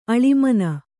♪ aḷimana